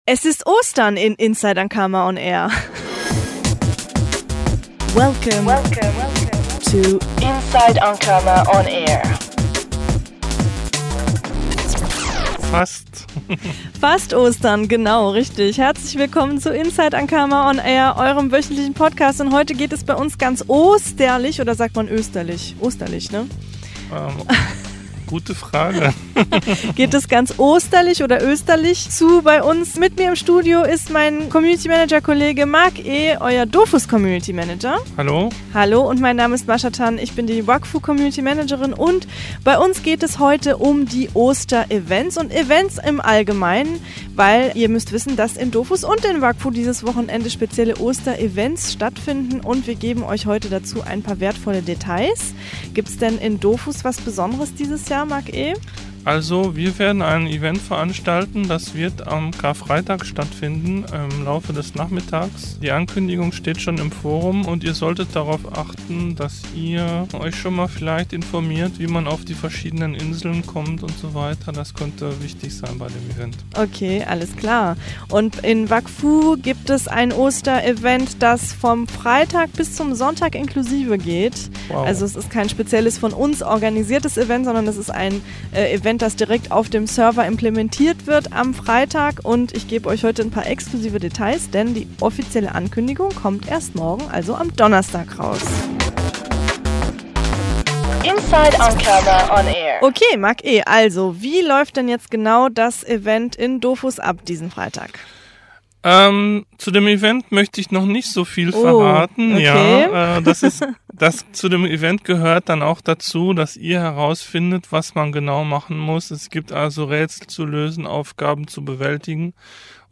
Eure Podcast-Moderatoren: